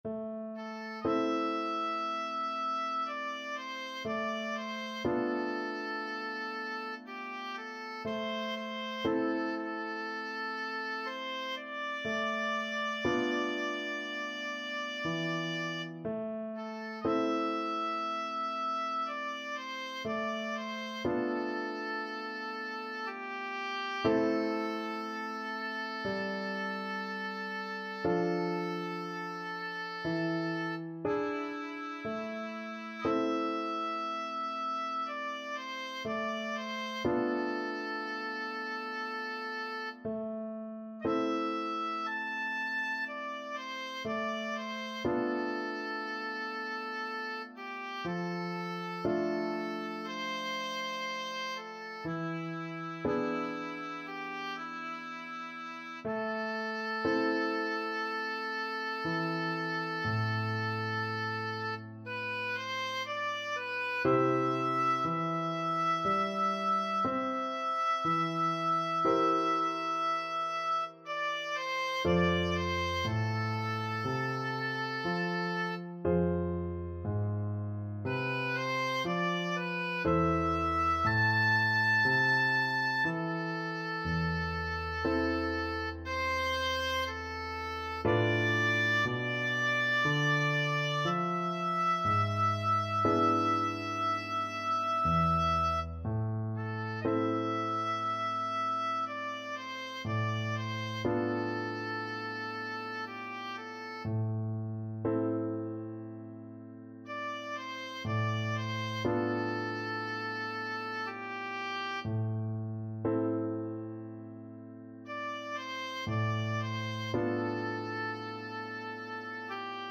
4/4 (View more 4/4 Music)
C major (Sounding Pitch) (View more C major Music for Oboe )
Lento, espressivo
Pop (View more Pop Oboe Music)